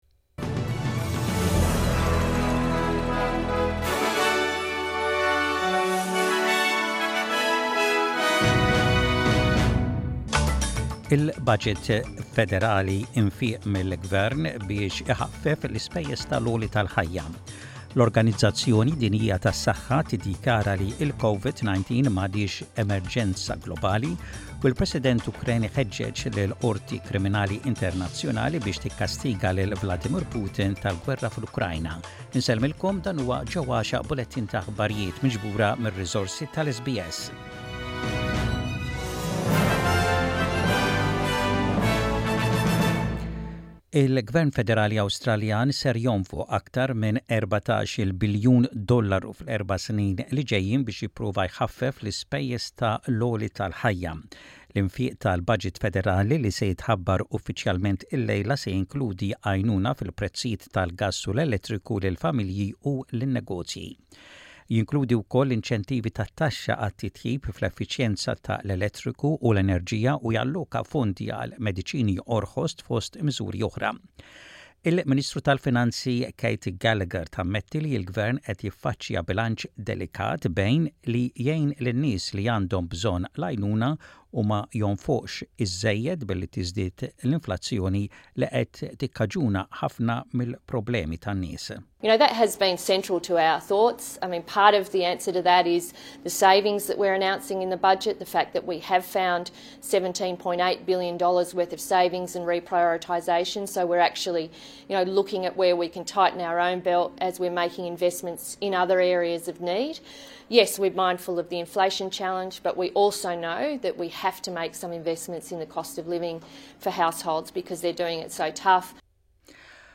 SBS Radio | Maltese News: 09/05/23